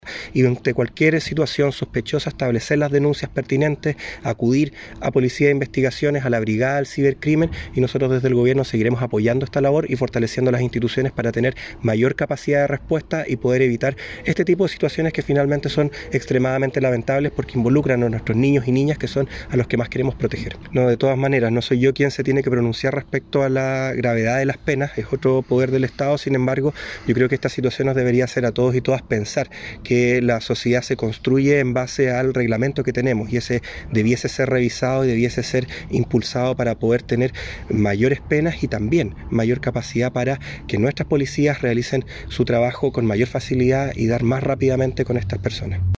El Delegado presidencial Rubén Quezada, felicitó el arduo trabajo de PDI, no obstante, recalcó que delitos como este debieran tener penas mayores.